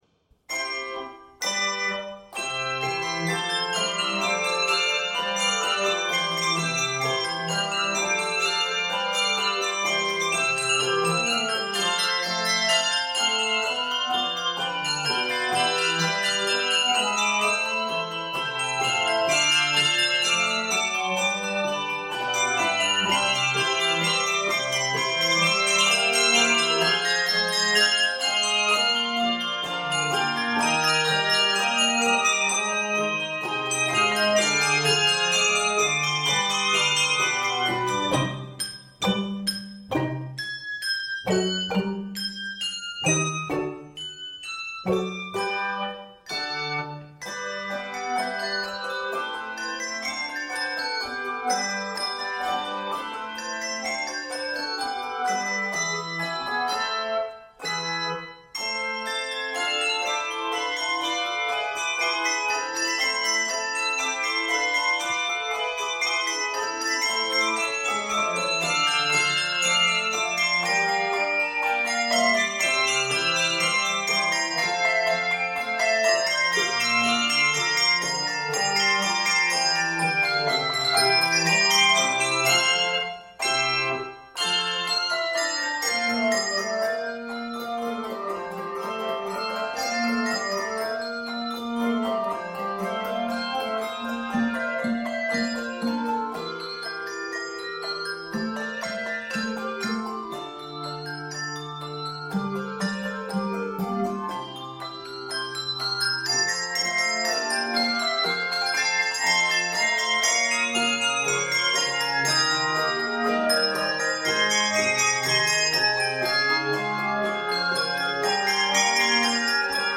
N/A Octaves: 3-6 Level